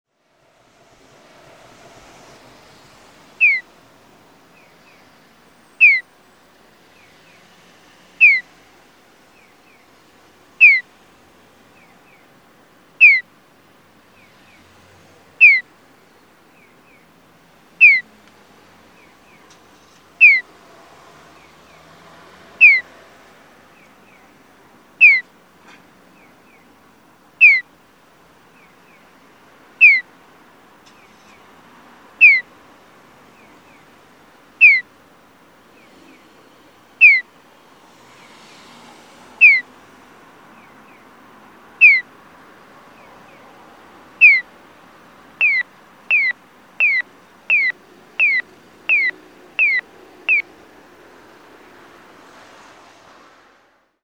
餅ケ浜4組(大分県別府市)の音響信号を紹介しています。